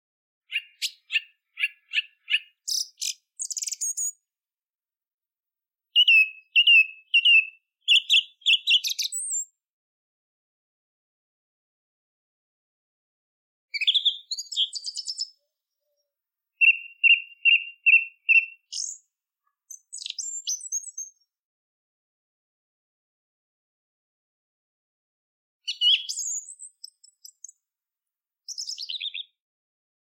Chant de la grive musicienne
Appréciée pour ses mélodies claires et apaisantes, la Grive musicienne trouve refuge dans les forêts de conifères où son chant résonne avec douceur.
Utilisé seul ou en combinaison avec d’autres boîtes à sons, le chant mélodieux de la Grive enveloppe l’espace d’une sensation de bien-être immédiate, créant un véritable moment d’évasion, surtout lorsqu’il s’harmonise avec les sons subtils de la forêt.
• Son : Chant de la Grive musicienne
Satellitebox_Song_Thrush_soundfile_30s.mp3